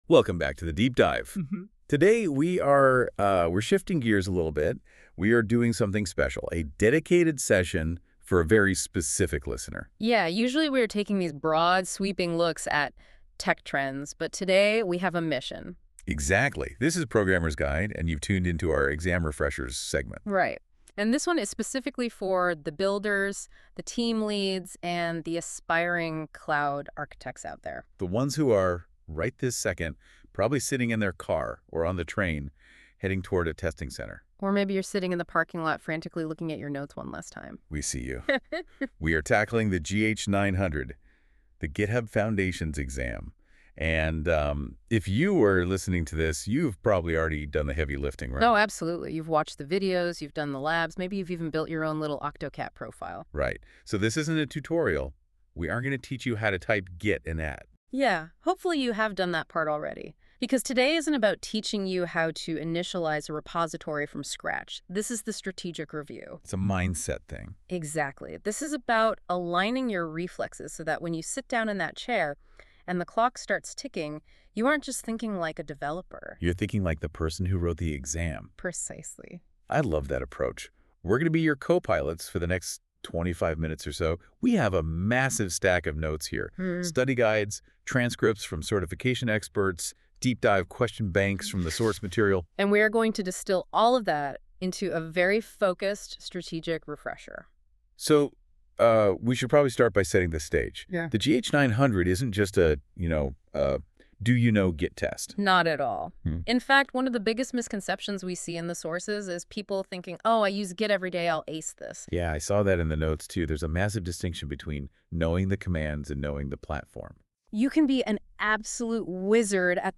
✨ Generated by NotebookLM
A podcast-style walkthrough of key exam topics.